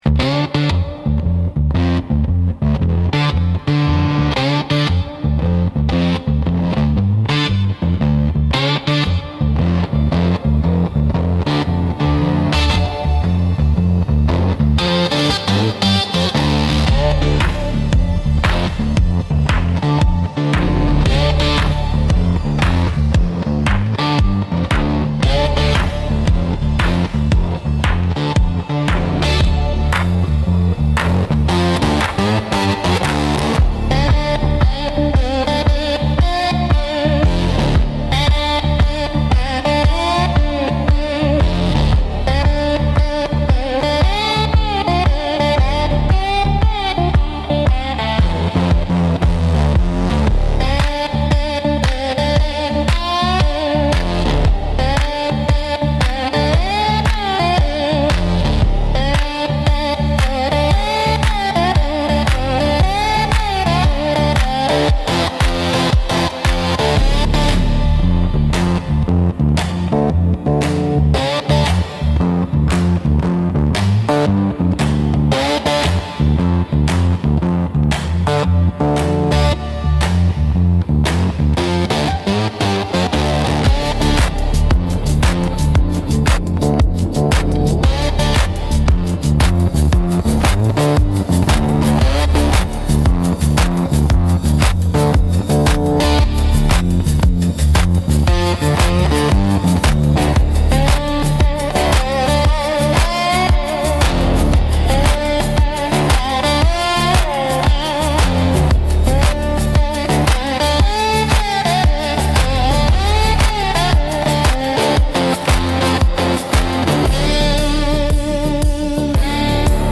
Instrumental - Eerie Porch Lights